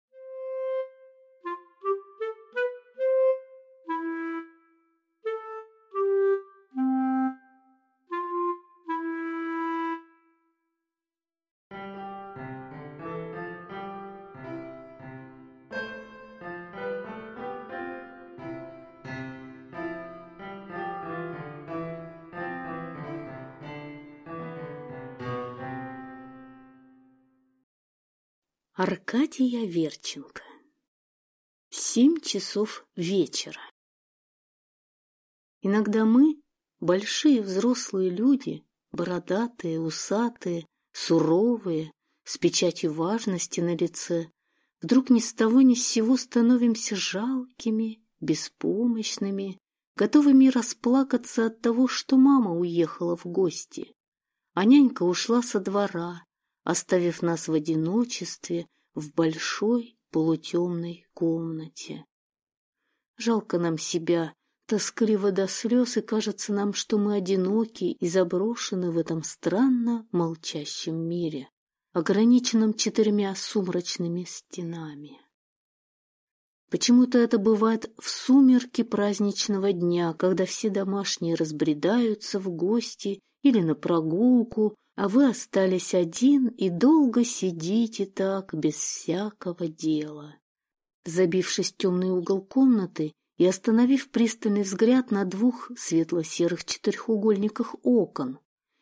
Аудиокнига Семь часов вечера | Библиотека аудиокниг